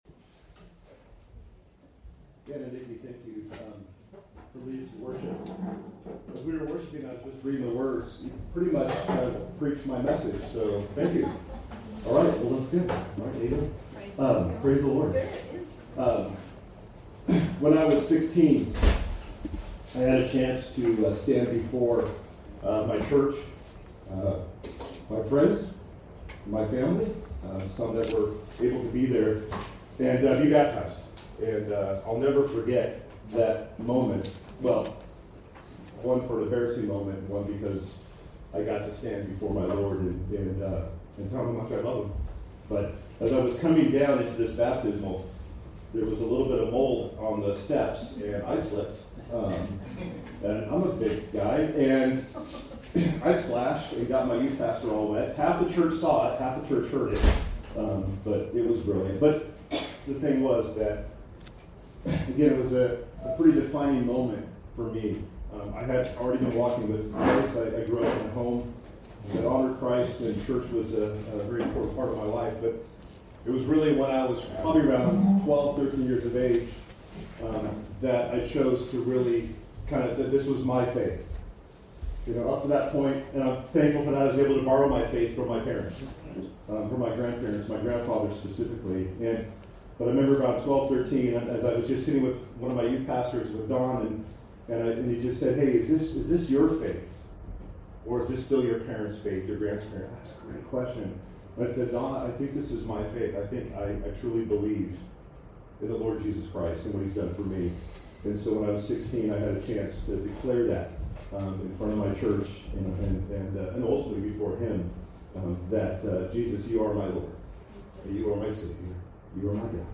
Sermons by OIKOS Church